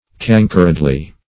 cankeredly - definition of cankeredly - synonyms, pronunciation, spelling from Free Dictionary Search Result for " cankeredly" : The Collaborative International Dictionary of English v.0.48: Cankeredly \Can"kered*ly\, adv.